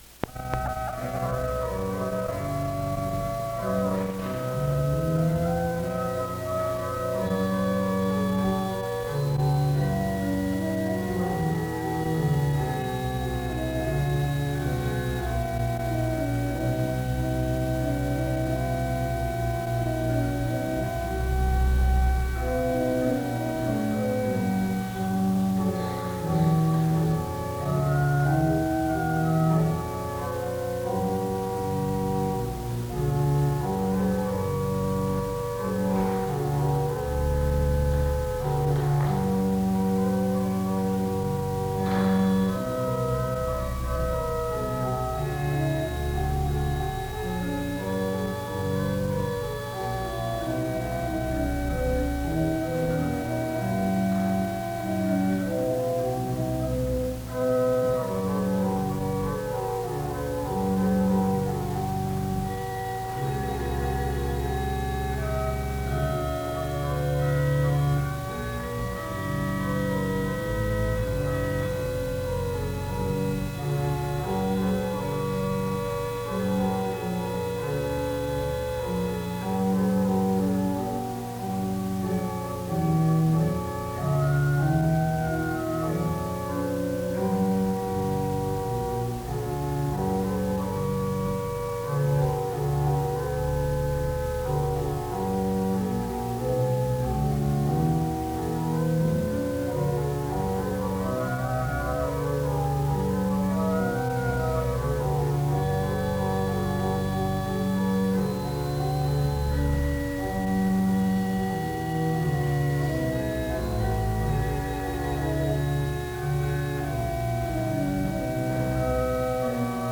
The service begins with music from 0:00-4:22. A responsive reading takes place from 4:30-6:18. A prayer announcement and prayer takes place from 6:29-12:44.
SEBTS Chapel and Special Event Recordings SEBTS Chapel and Special Event Recordings